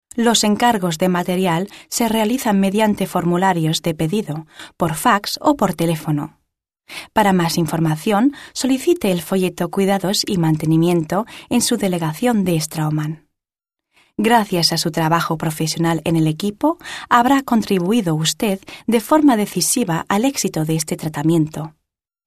» Spanisch f.
span[1]._Imagefilm_straumann_.mp3